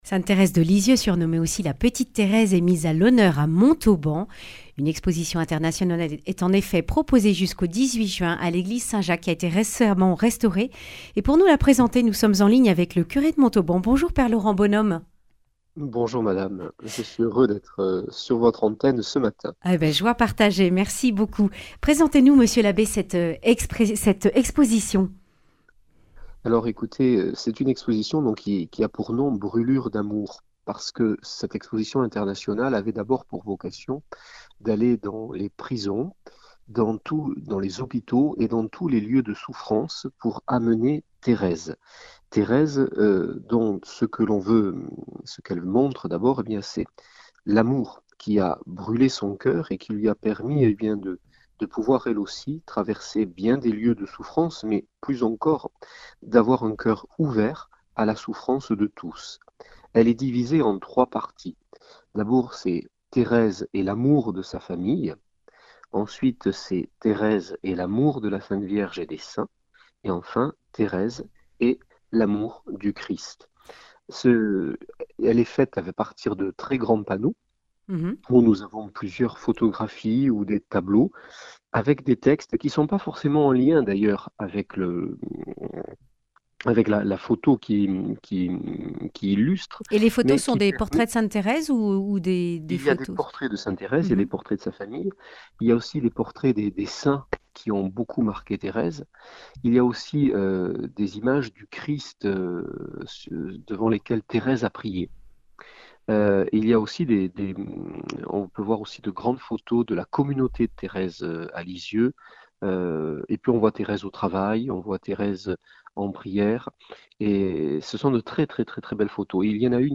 lundi 15 mai 2023 Le grand entretien Durée 10 min